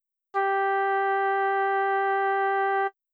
Sol jouée à la flûte.
flute_sol3.wav